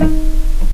vc_pz-D#4-pp.AIF